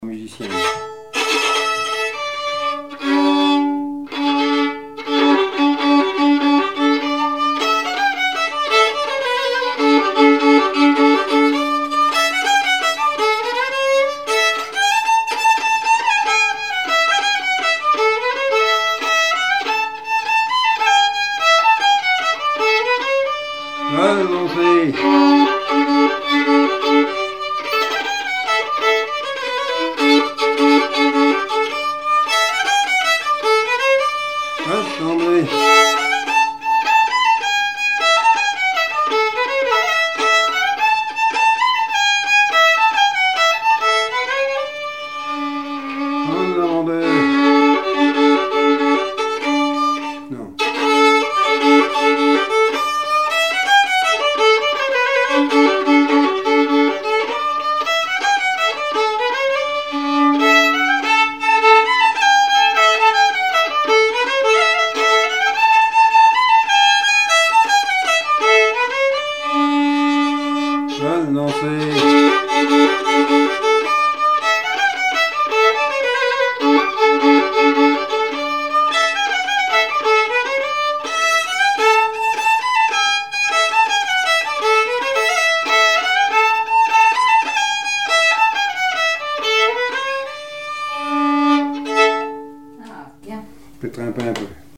danse : branle : avant-deux
Témoignages et chansons
Pièce musicale inédite